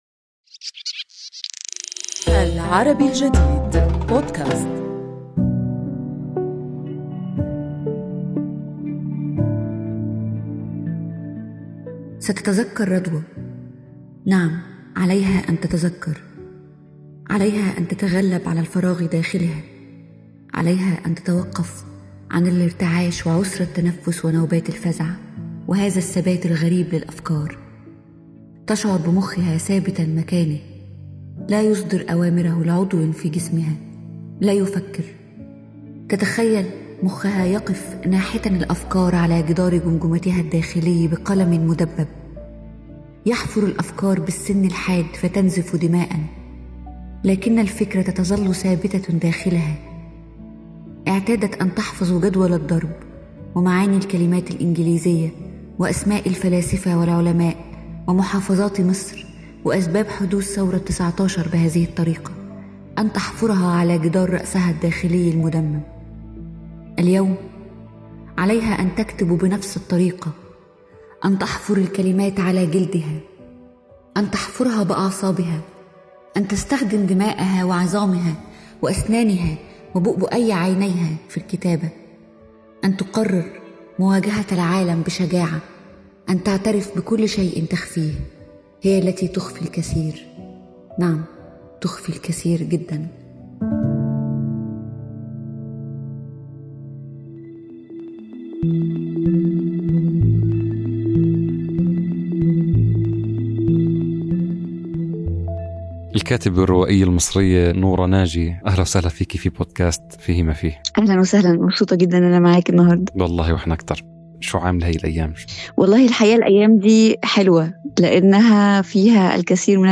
ونعرض نصوصاً من كتابة ضيفتنا قرأتها لمصلحة برنامجنا.